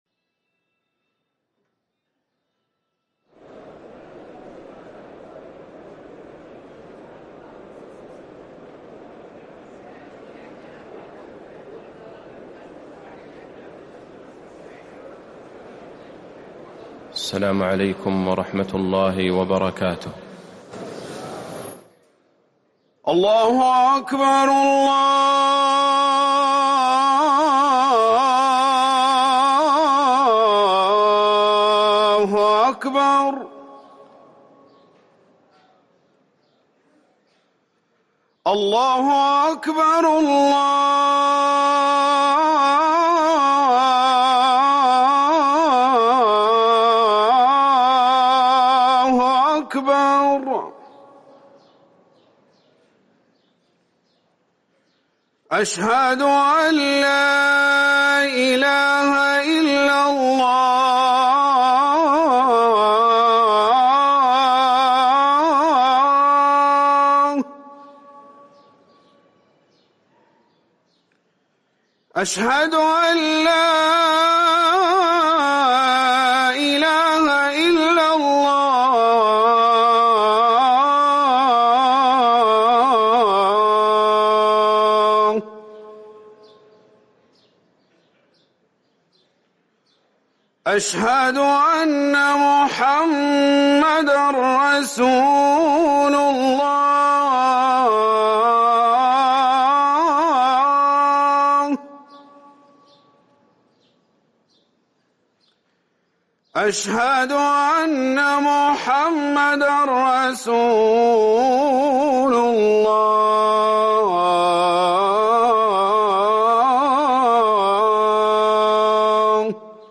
أذان الجمعة الثاني